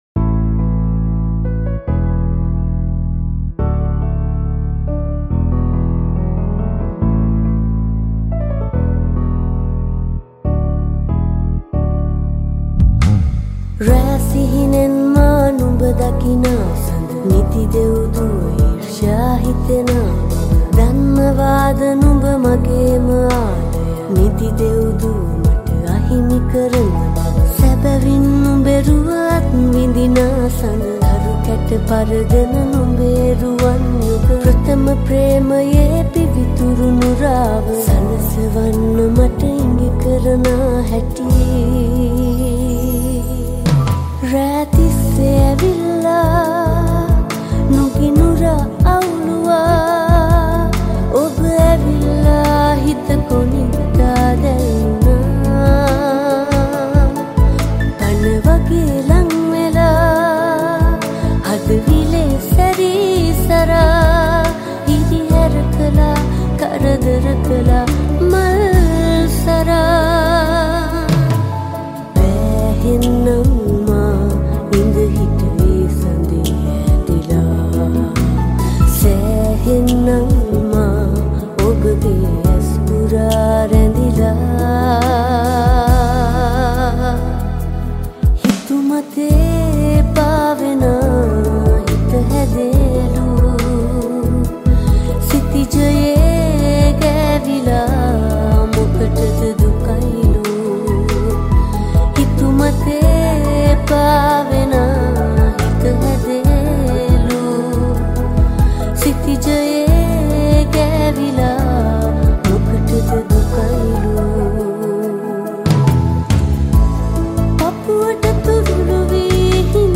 Voice
Keys